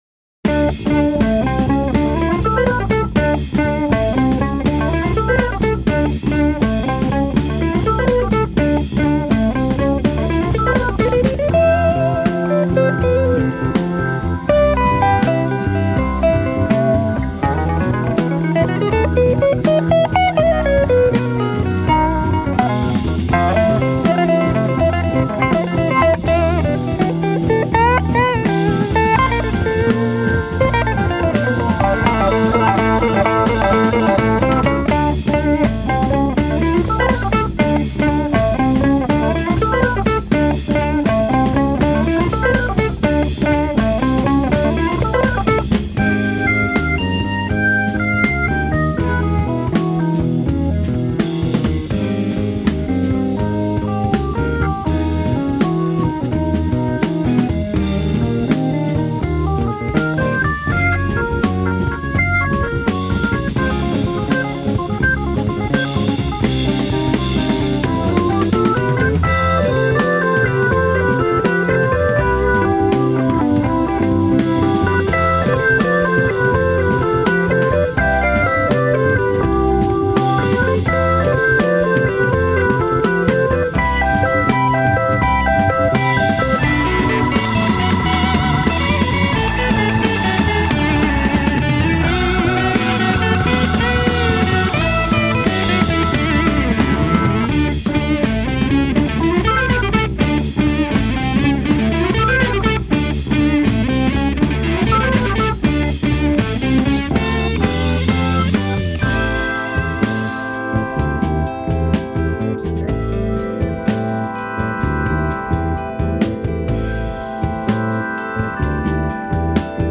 отчетливо прослушиваются кентерберийские корни.